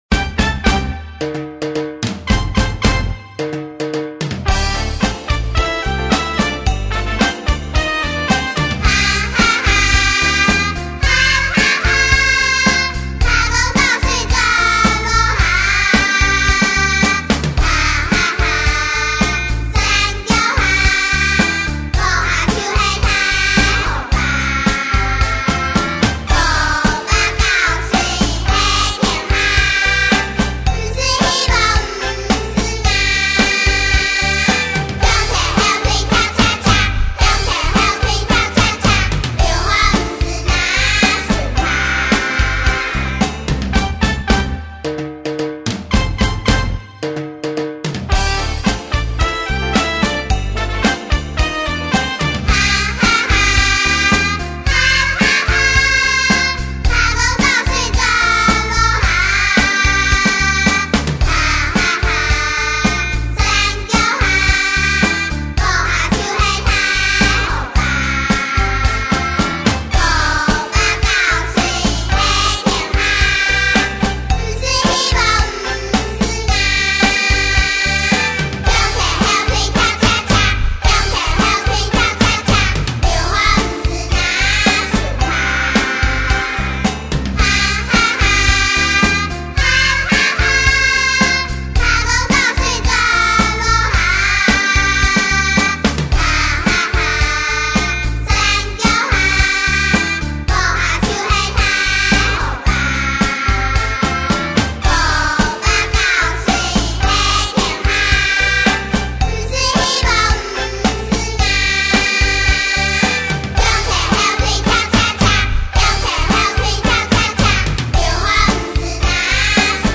蝦公搞水(完整演唱版) | 新北市客家文化典藏資料庫